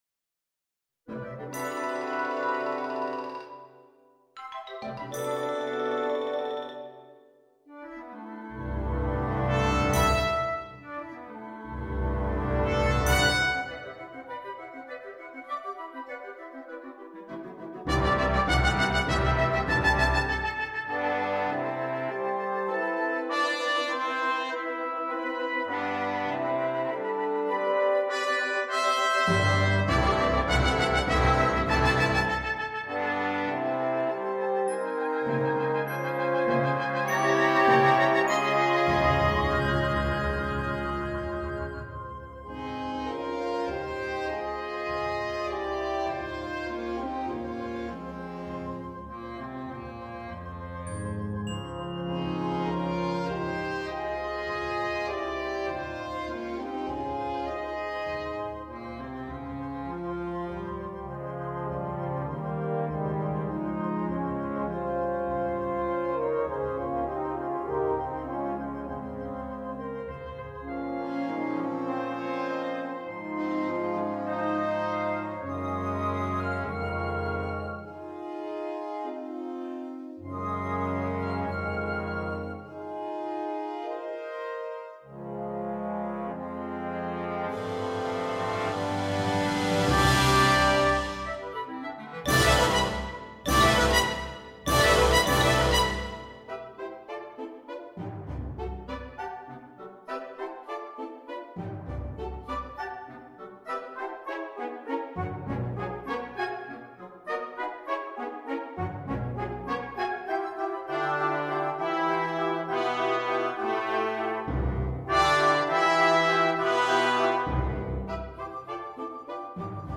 (Piece for Wind Band)